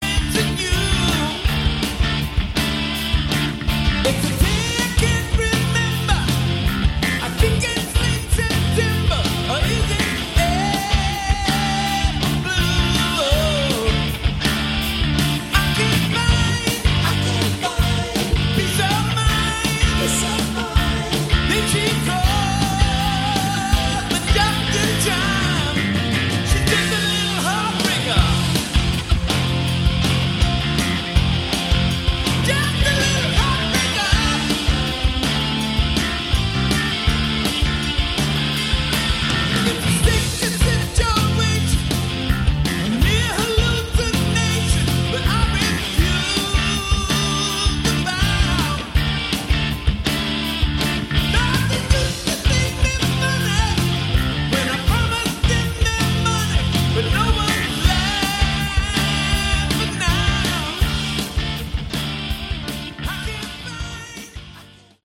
Category: AOR
live